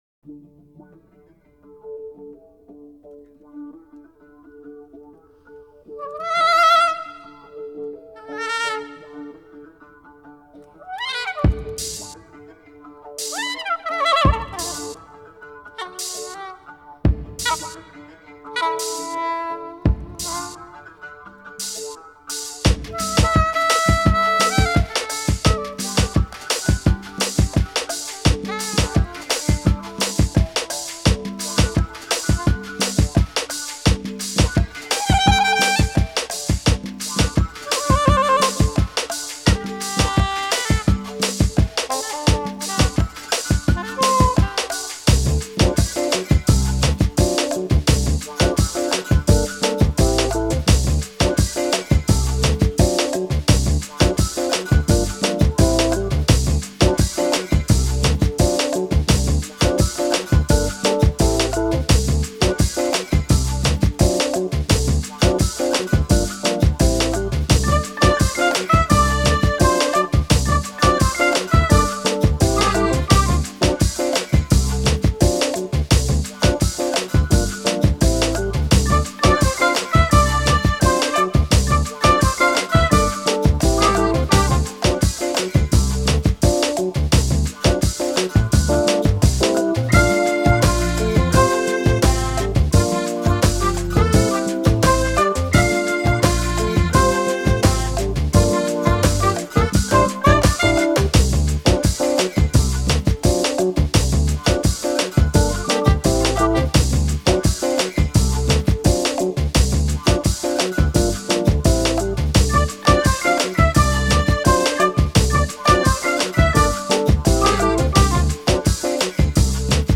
Latin Jazz: